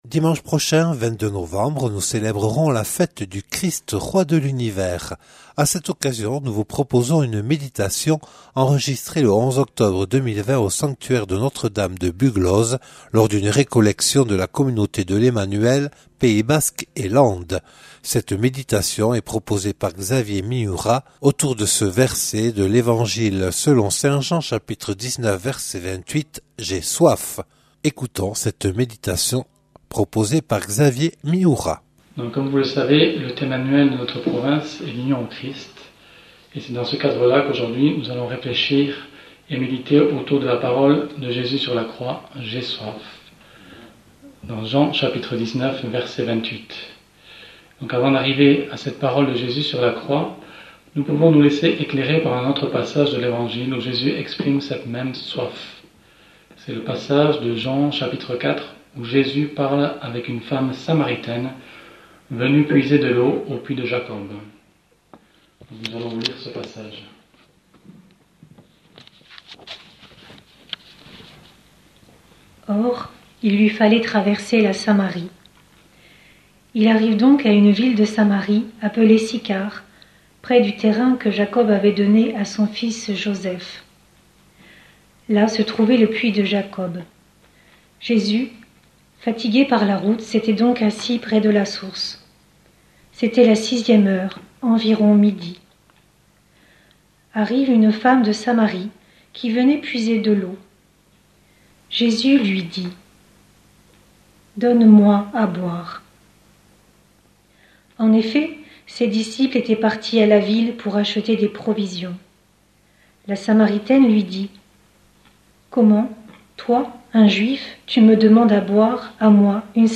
Enregistrée le 11 octobre 2020 au sanctuaire de Buglose lors d’une récollection de la Communauté de l’Emmanuel Pays Basque et Landes